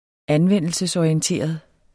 Udtale [ -ɒiənˌteˀʌð ]